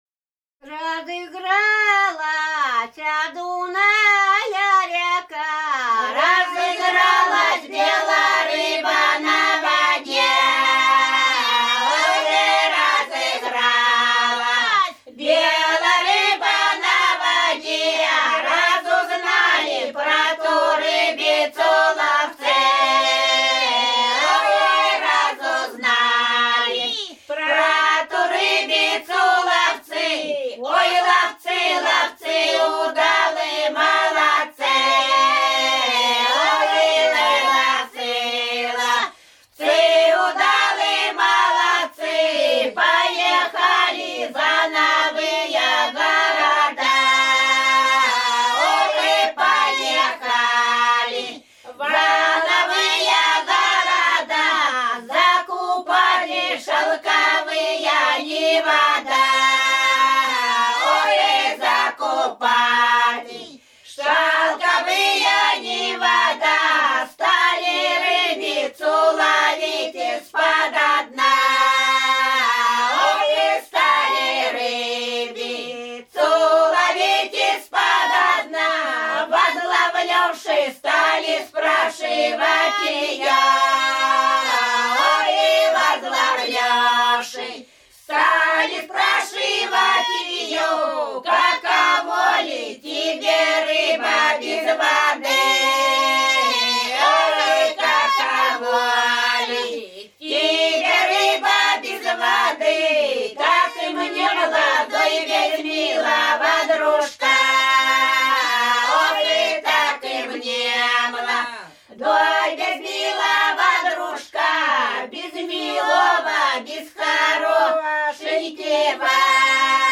Рязань Кутуково «Разыгралася Дуная-ряка», плясовая.